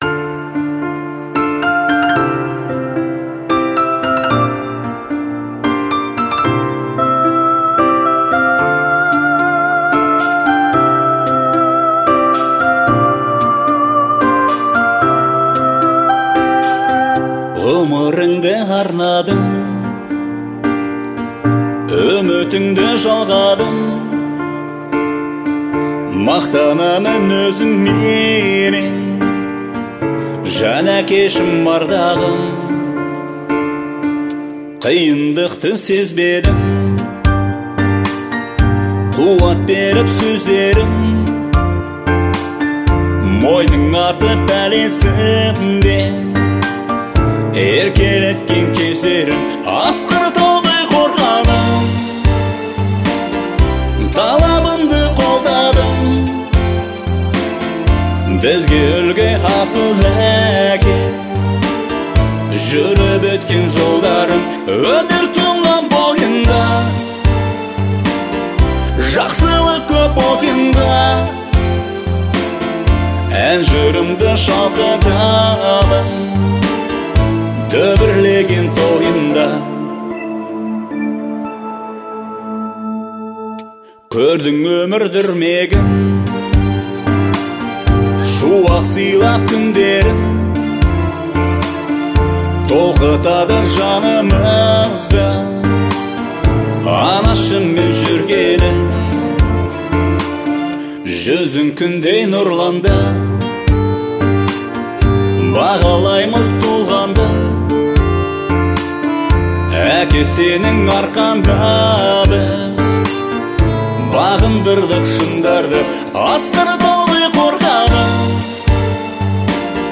Звучание мелодии отличается душевностью и глубиной